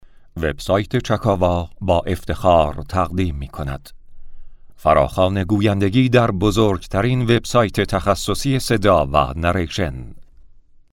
hemasi.mp3